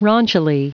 Prononciation du mot : raunchily
raunchily.wav